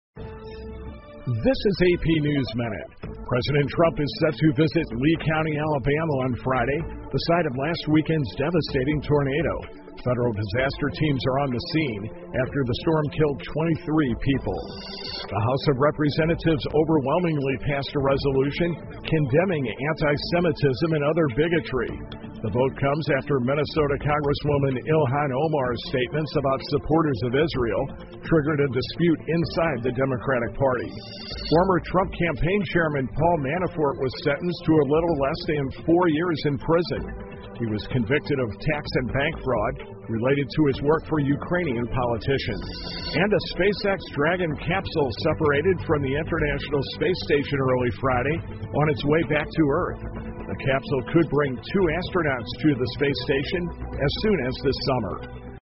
美联社新闻一分钟 AP SpaceX太空舱返回地球 听力文件下载—在线英语听力室